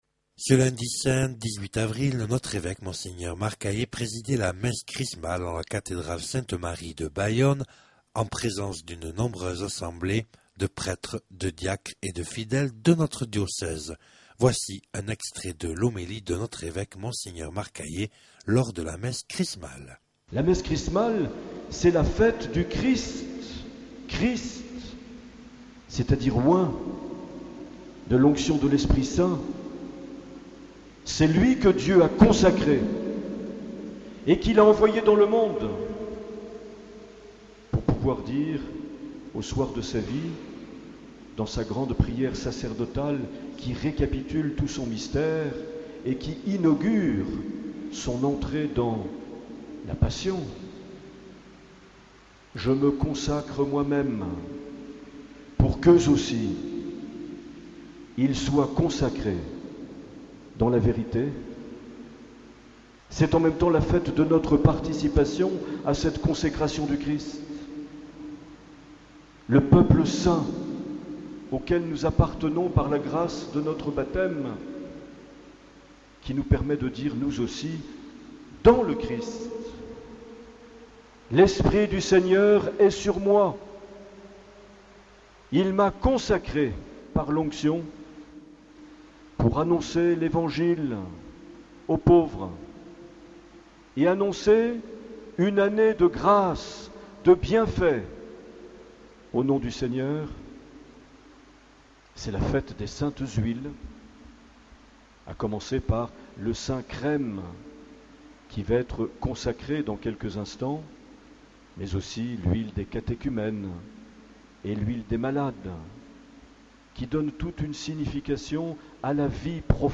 18 avril 2011 - Cathédrale de Bayonne - Messe Chrismale
Les Homélies
Une émission présentée par Monseigneur Marc Aillet